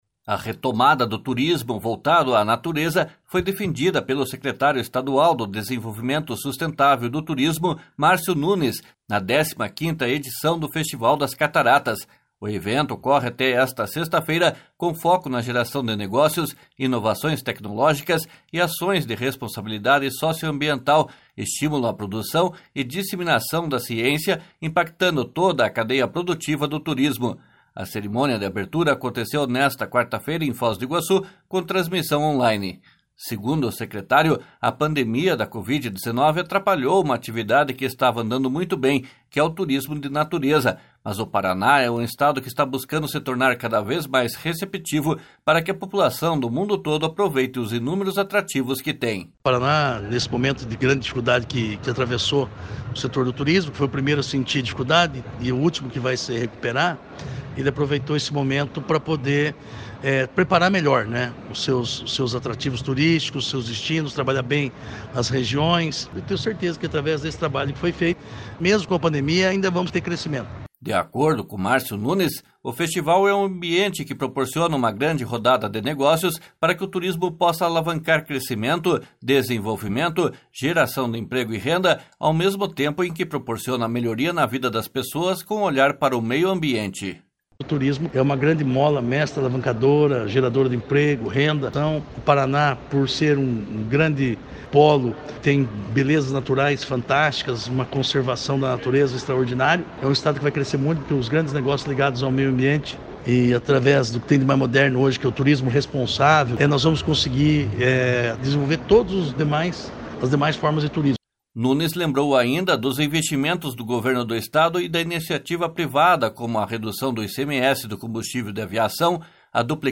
A cerimônia de abertura aconteceu nesta quarta-feira, em Foz do Iguaçu, com transmissão online.
//SONORA MARCIO NUNES//